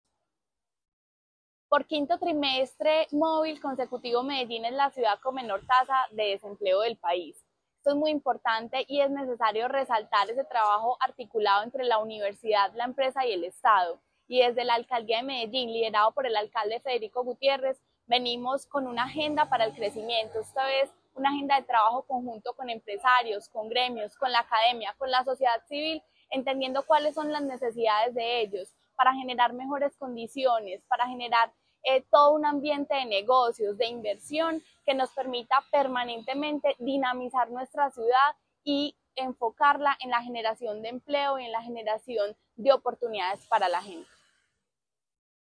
Palabras de María Fernanda Galeano, secretaria de Desarrollo Económico